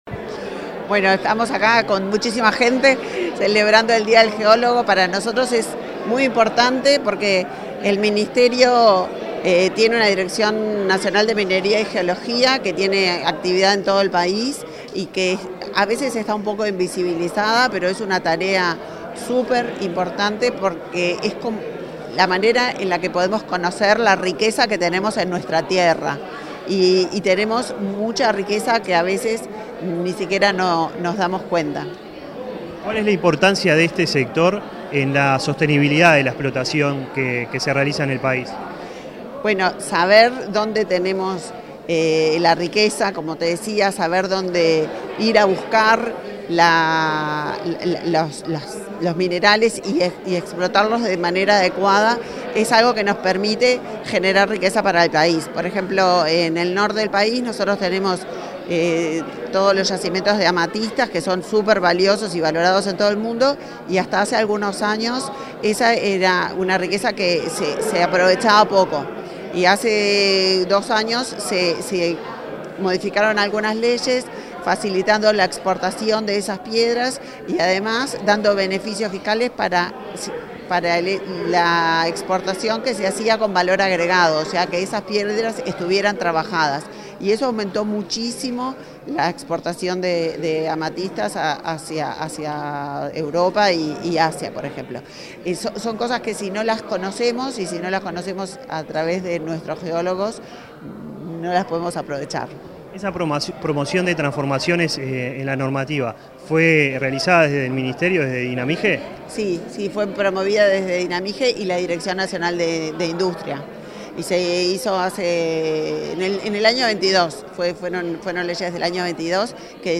Entrevista a la ministra del MIEM, Elisa Facio
Entrevista a la ministra del MIEM, Elisa Facio 28/05/2024 Compartir Facebook X Copiar enlace WhatsApp LinkedIn Este 28 de mayo, la Dirección Nacional de Minería y Geología (Dinamige) de Ministerio de Industria, Energía y Minería (MIEM) realizó el acto de celebración por el Día del Geólogo. Tras el evento, la ministra Elisa Faccio realizó declaraciones a Comunicación Presidencial.